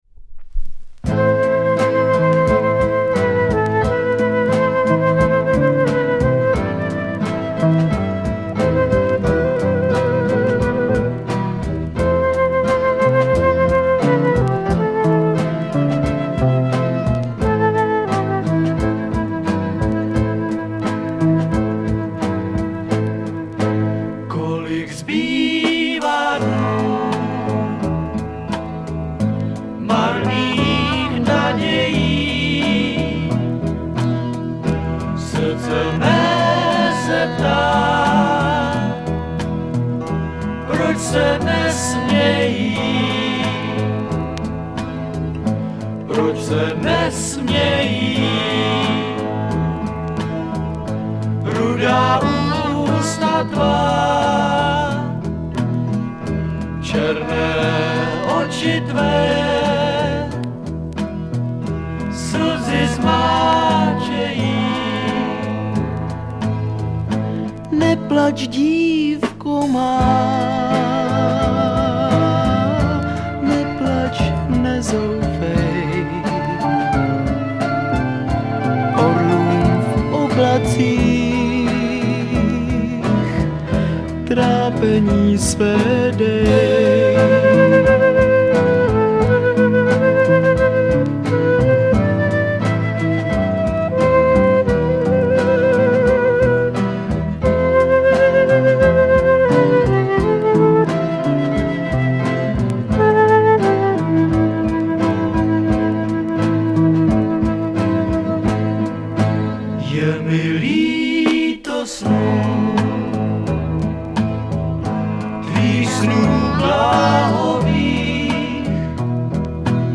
армянскую народную
Поют чехи - 100%.